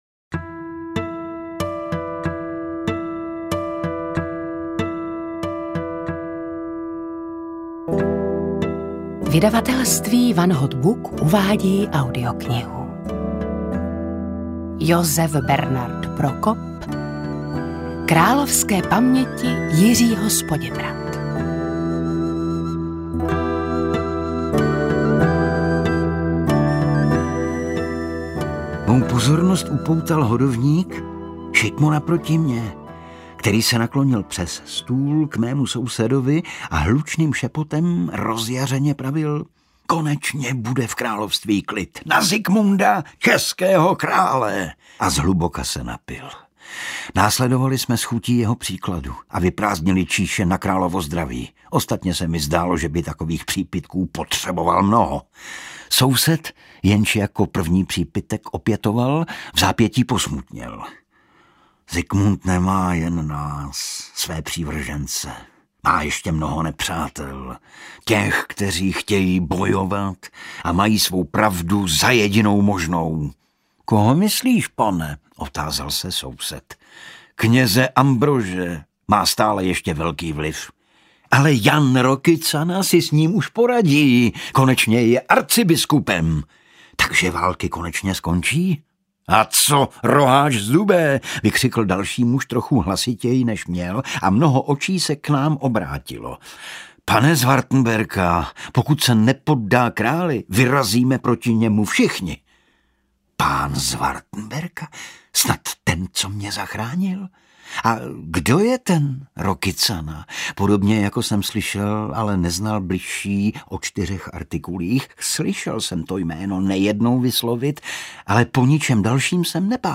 Audio knihaKrálovské paměti Jiřího z Poděbrad
Ukázka z knihy
kralovske-pameti-jiriho-z-podebrad-audiokniha